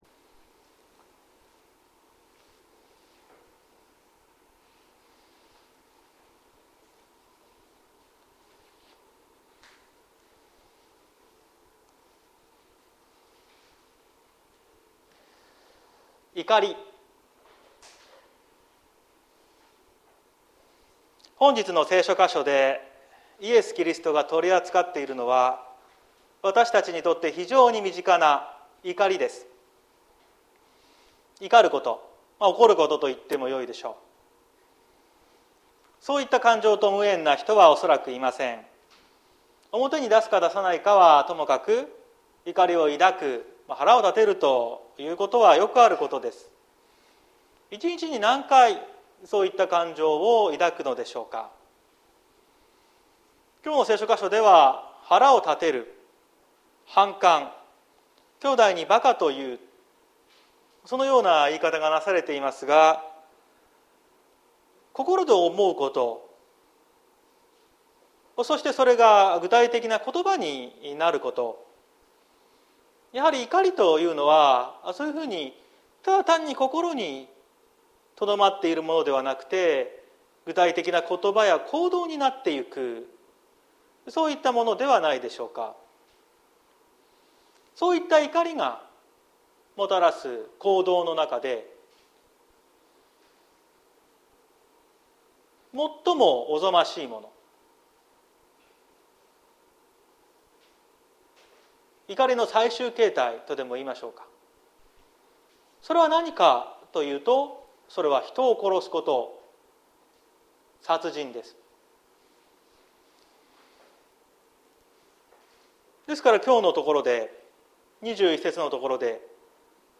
2022年10月02日朝の礼拝「怒りに支配される前に」綱島教会
綱島教会。説教アーカイブ。